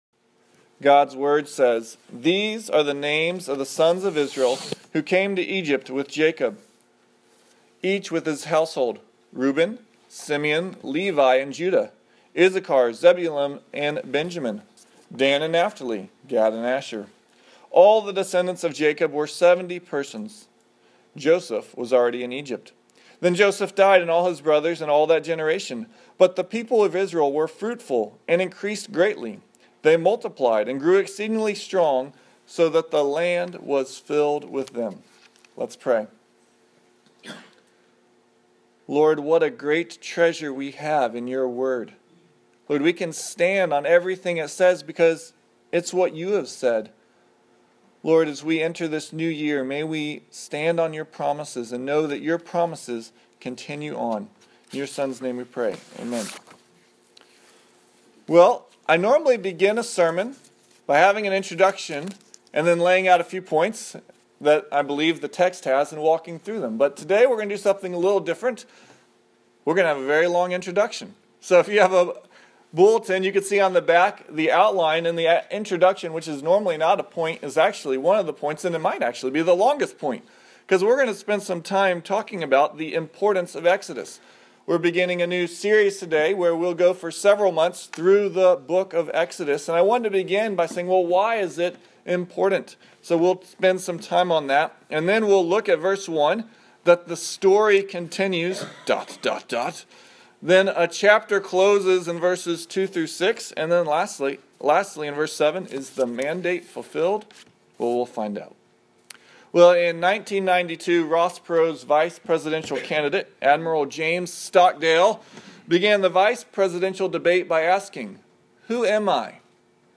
General Sermons